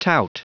Prononciation du mot tout en anglais (fichier audio)
Prononciation du mot : tout